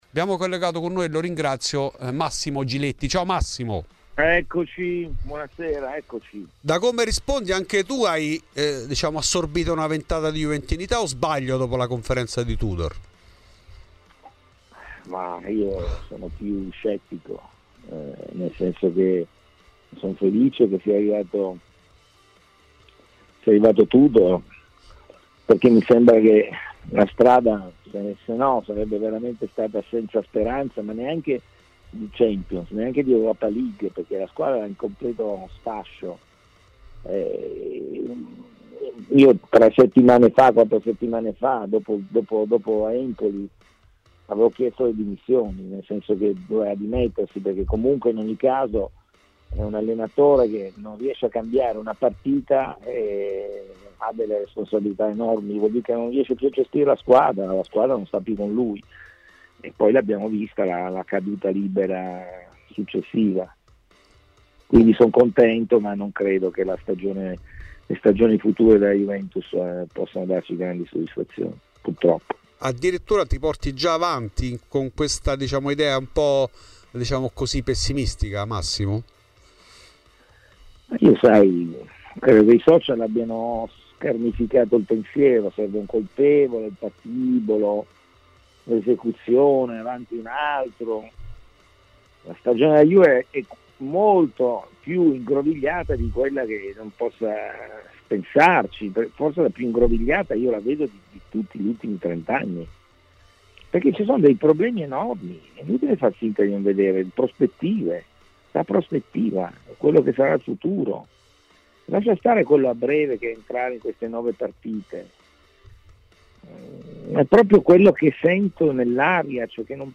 In ESCLUSIVA a Fuori di Juve il giornalista Massimo Giletti.